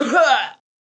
backdamage_flying.wav